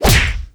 PunchHit2.wav